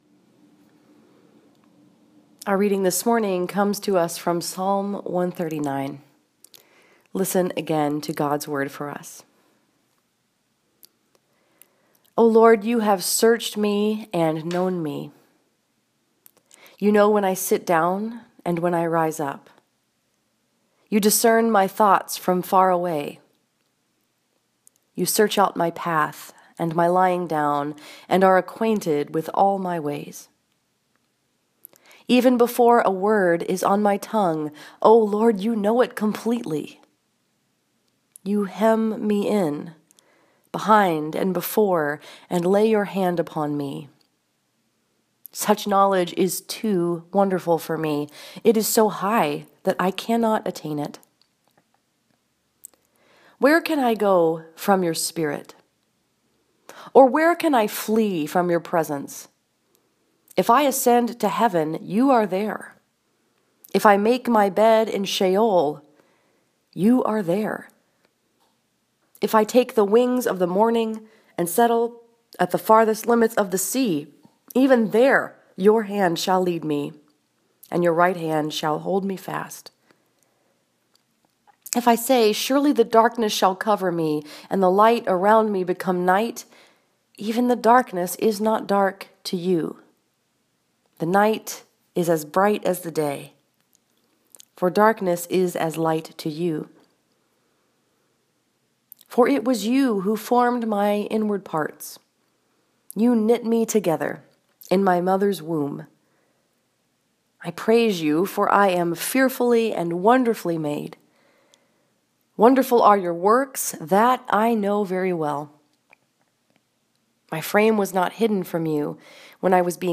This sermon was preached at Northside Presbyterian Church in Ann Arbor, Michigan and is based on Psalm 139.
sermon.m4a